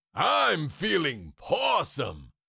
Cartoon Lion, Voice, I Am Feeling Pawsome Sound Effect Download | Gfx Sounds
Cartoon-lion-voice-i-am-feeling-pawsome.mp3